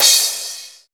percussion 24.wav